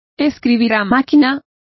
Complete with pronunciation of the translation of types.